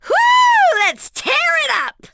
[WAV] Grandia II's Millenia Voiceset